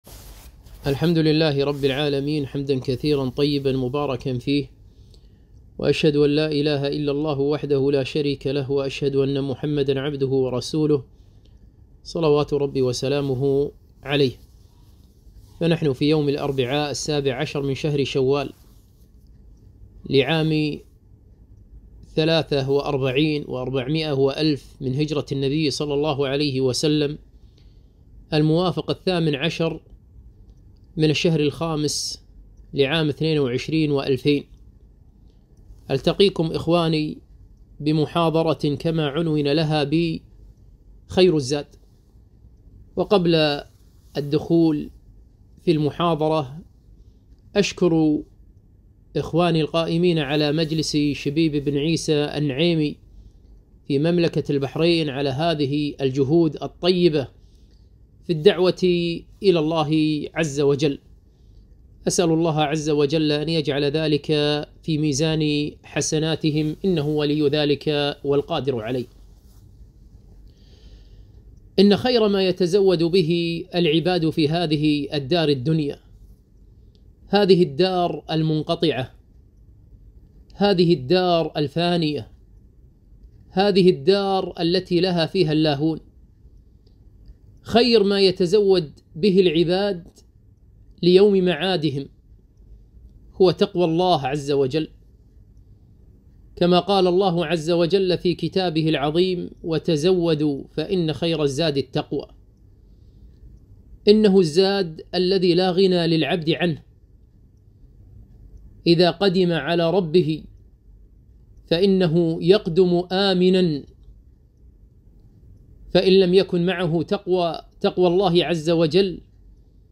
كلمة - خير الزاد